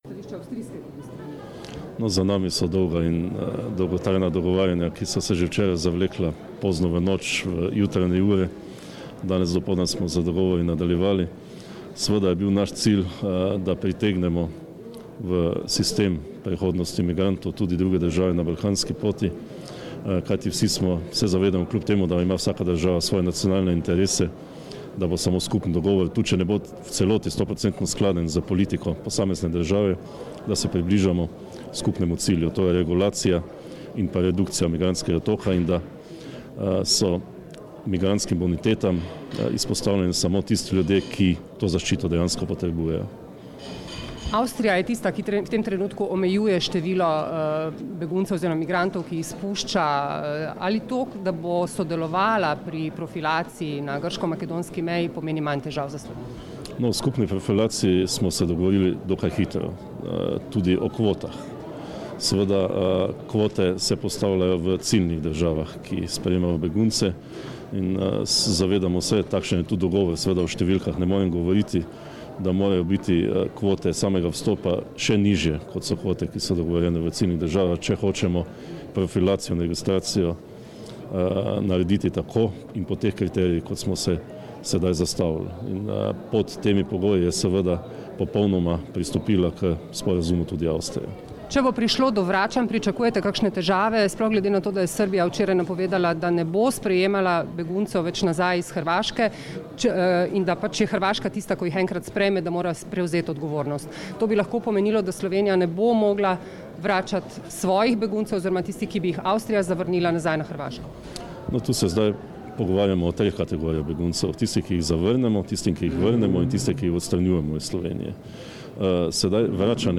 Zvočni posnetek izjave generalnega direktorja policije Marjana Fanka (mp3)